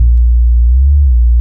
A#_07_Sub_07_SP.wav